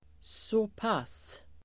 Ladda ner uttalet
såpass adverb, (about) soUttal: [s'å:pas] Variantform: även så passDefinition: ungefär såExempel: såpass mycket bör man veta (one should know about so (this) much)